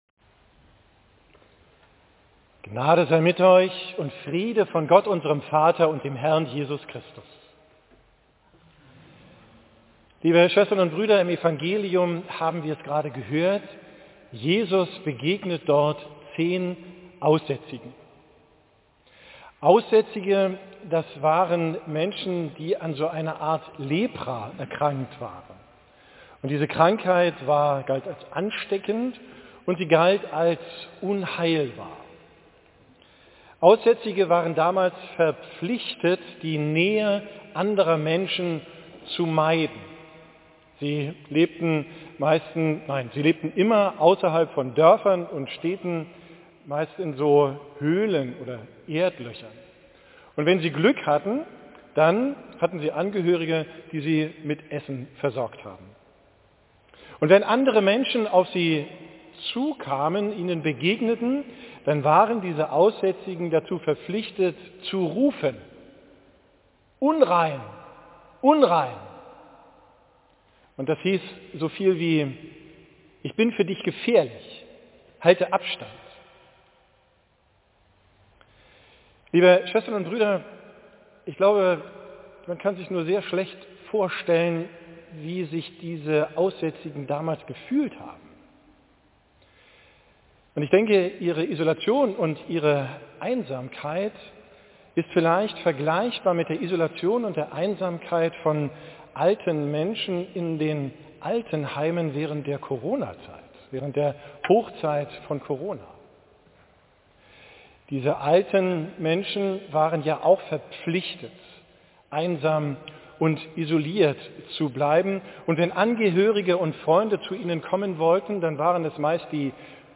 Alttestamentliche Lesung 1.